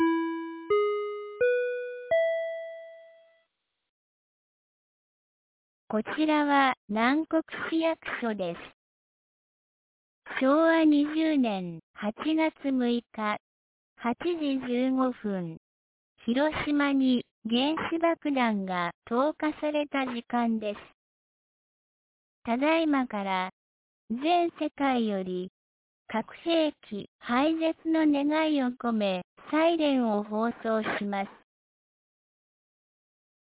2025年08月06日 08時14分に、南国市より放送がありました。